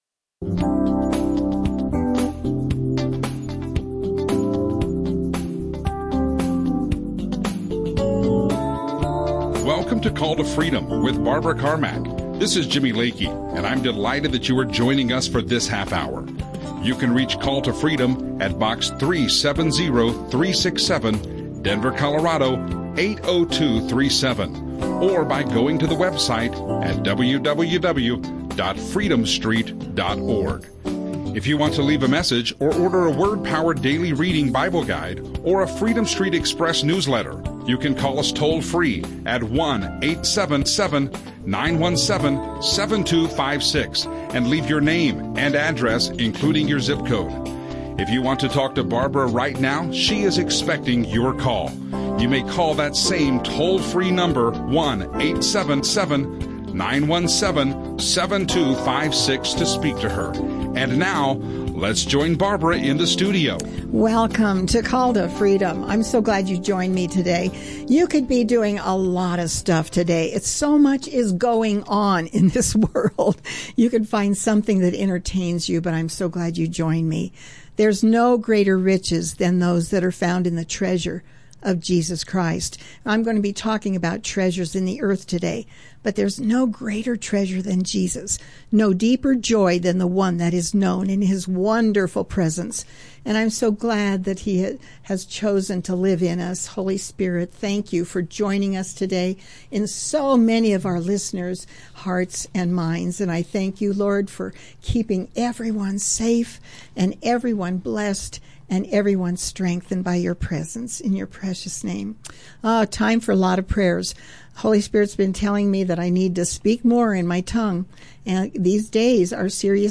Biblical teaching